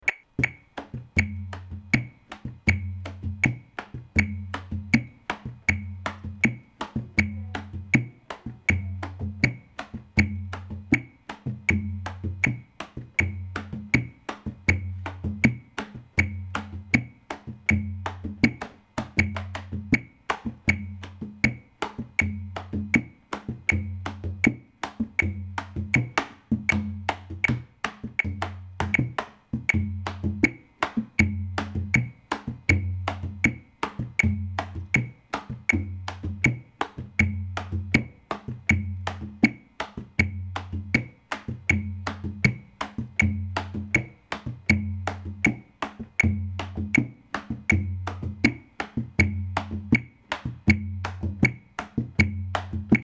rebolo tempo=80
rebolo_80.mp3